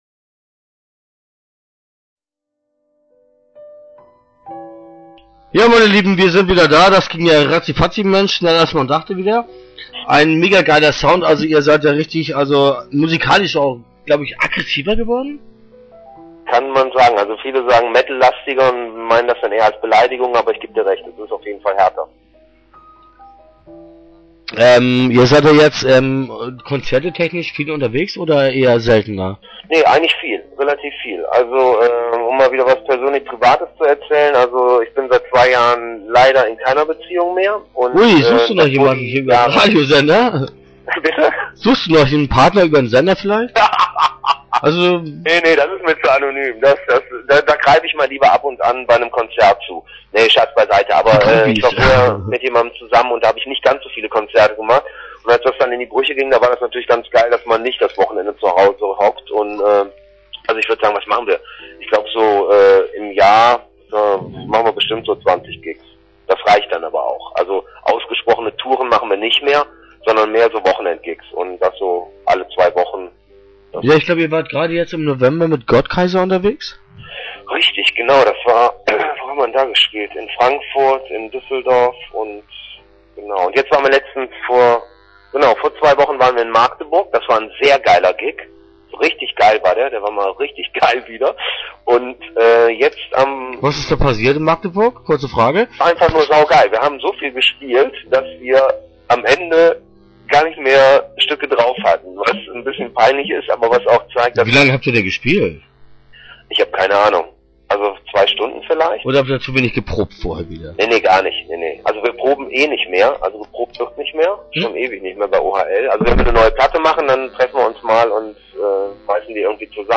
Interview Teil 1 (12:46)